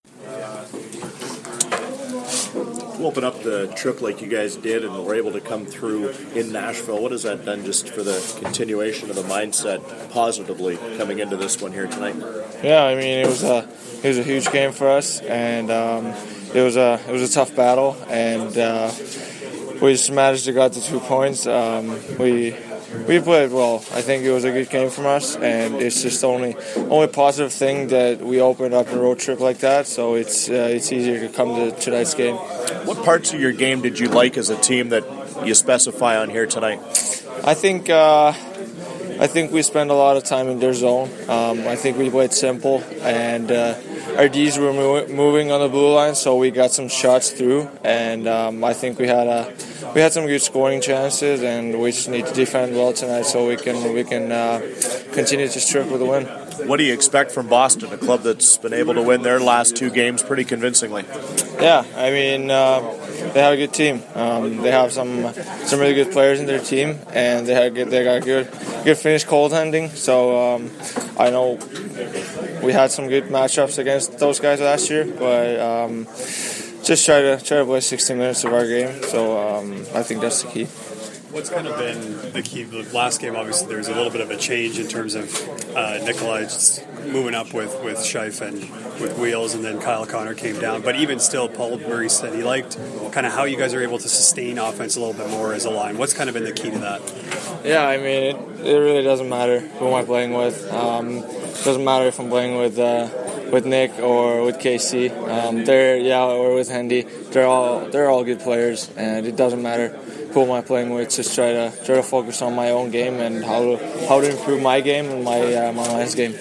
Pre-game audio:
Audio courtesy of TSN 1290 Winnipeg.